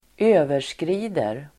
Uttal: [²'ö:ver_skri:der]